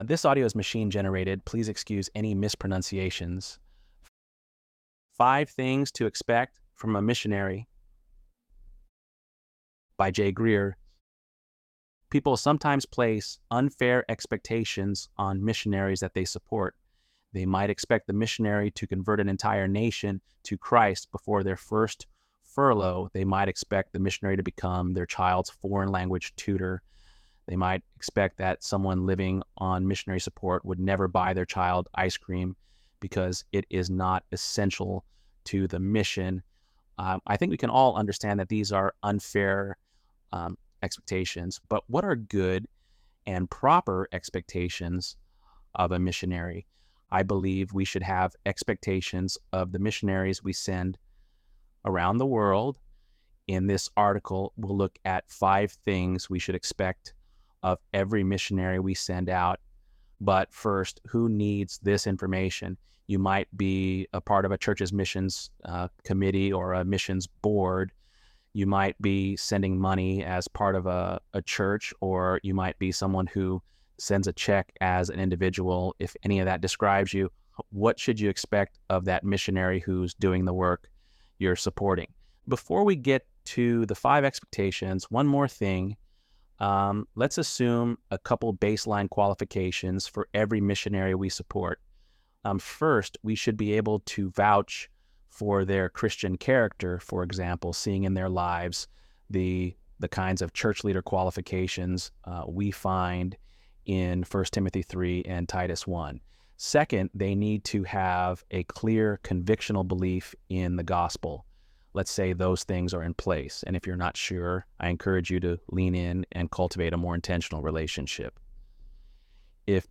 ElevenLabs_11_19.mp3